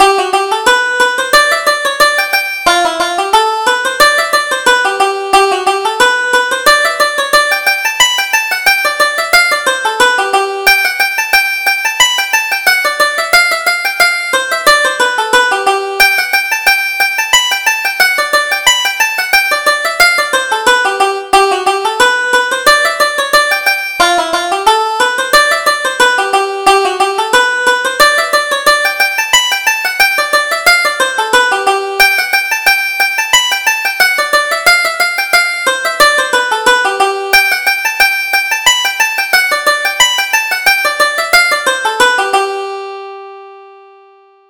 Reel: The Mills Are Grinding - 1st Setting